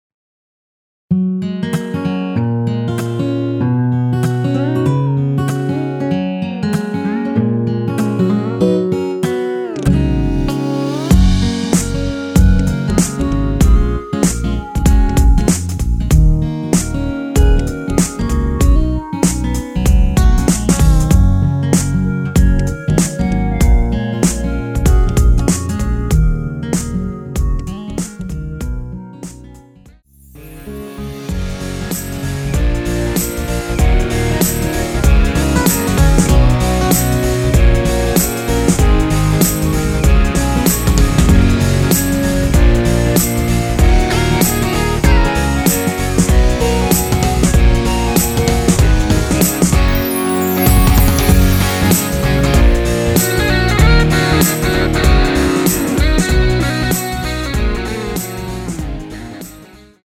원키에서(-2)내린 멜로디 포함된 MR입니다.
Gb
앞부분30초, 뒷부분30초씩 편집해서 올려 드리고 있습니다.
중간에 음이 끈어지고 다시 나오는 이유는